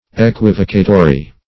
Equivocatory \E*quiv"o*ca*to*ry\, a.
equivocatory.mp3